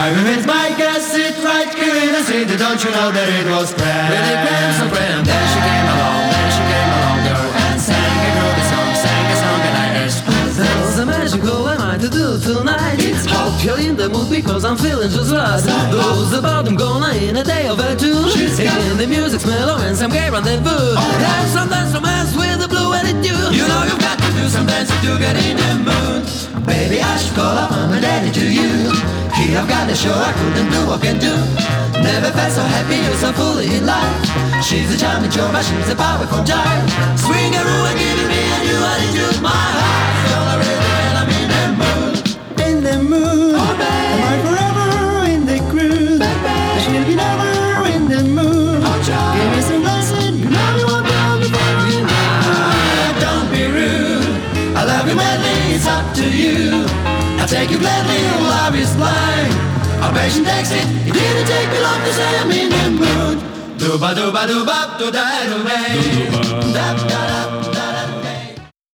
męski kwartet wokalny